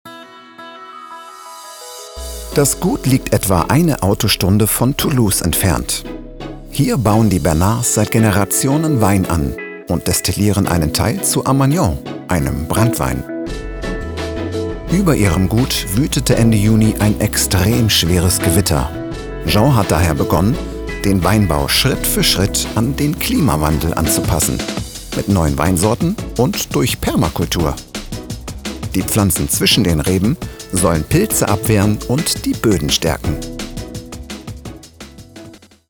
dunkel, sonor, souverän, plakativ
Mittel plus (35-65)
Kommentar 01 - Klima
Comment (Kommentar), Doku, Off, Narrative, Overlay